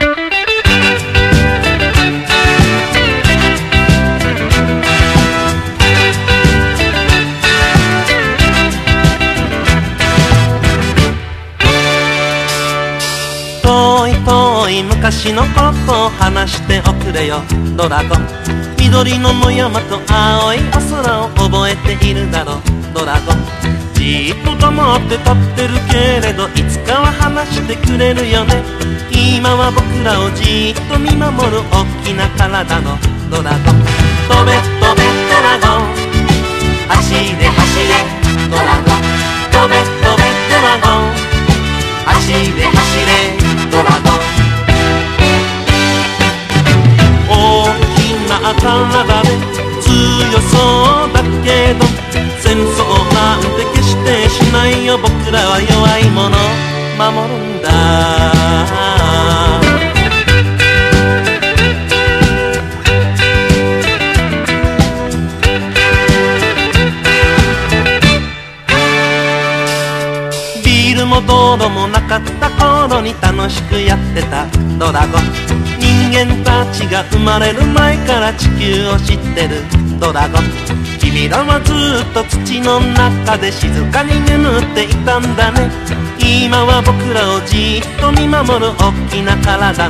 FOLK / JAPANESE SOFT ROCK / NEW ROCK (JPN)